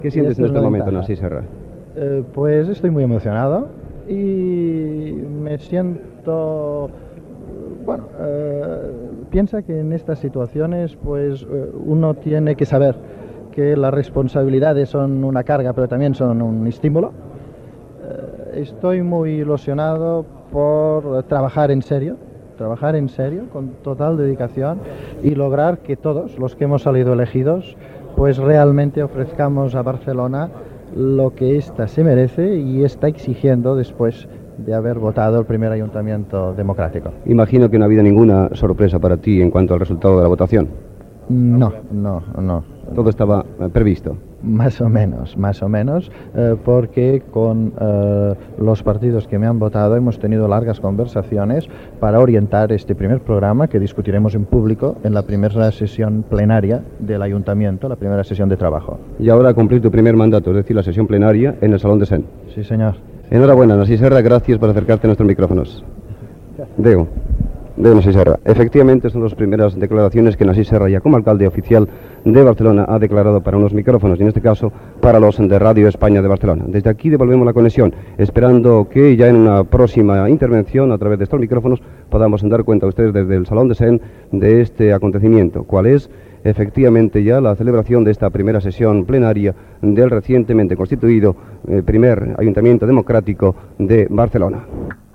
Entrevista al recentment escollit alcalde de Barcelona, Narcís Serra
Informatiu